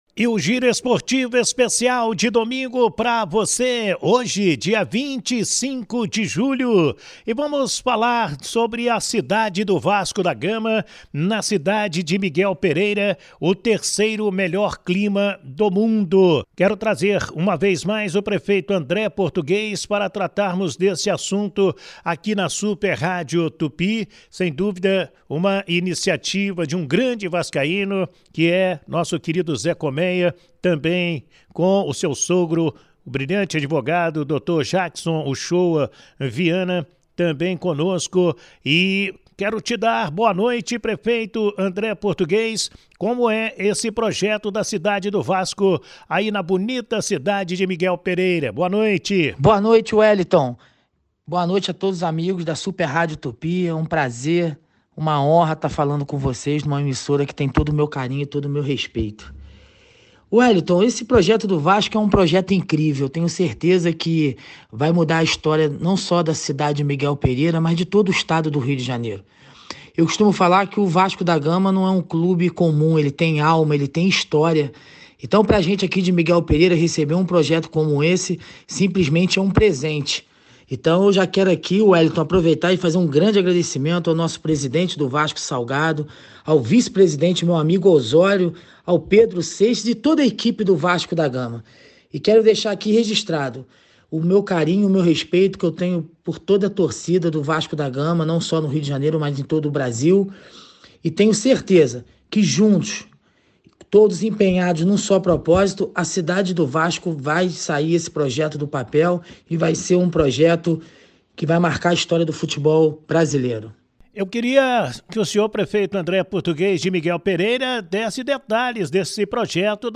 Prefeito de Miguel Pereira fala sobre o projeto Cidade do Vasco; ouça | NETVASCO
Neste domingo (25/7), a Super Rádio Tupi produziu um material exclusivo sobre o tema, mostrando o Projeto Esportivo/Turístico, tendo o Vasco da Gama como grande tema. Serão construídos um hotel de categoria 5 estrelas, ginásio, mini estádio, lojas, restaurantes e até o Lago Javary receberá o remo vascaíno.